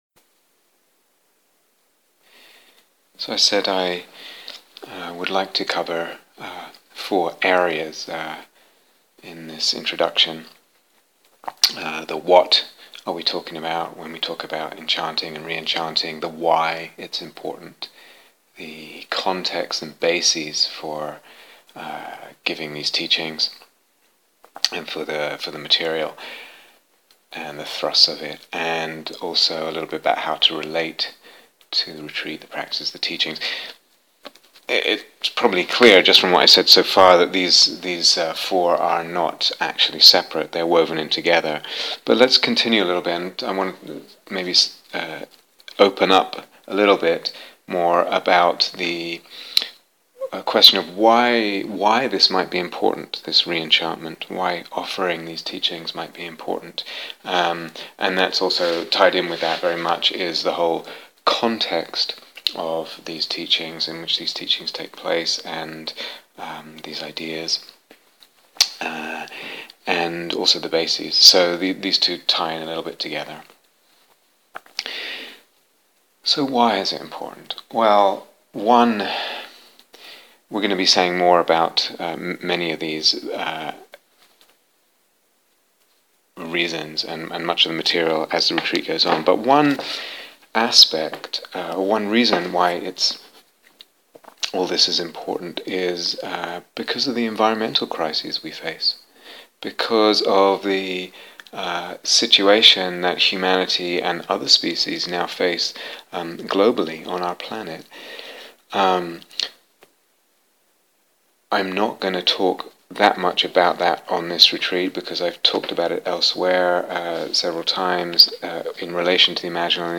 Here is the full retreat on Dharma Seed Please Note